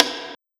SNARE135.wav